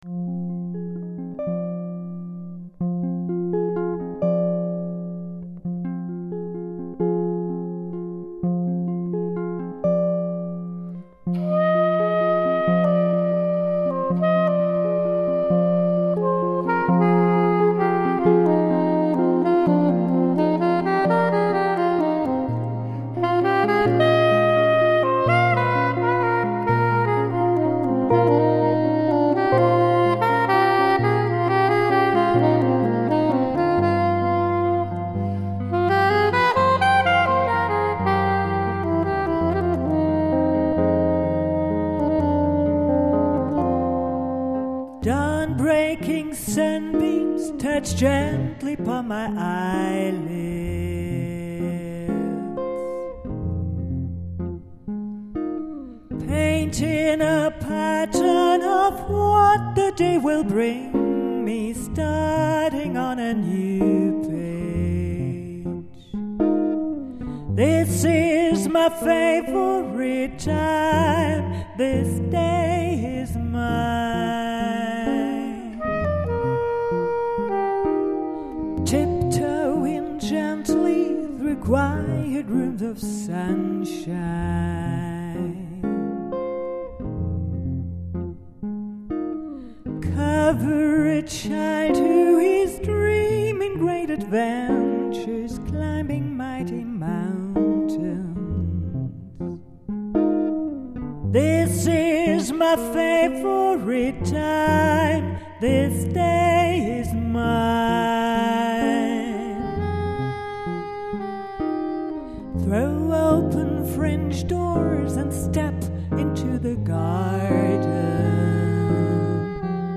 Probe (Castle, Saarburg)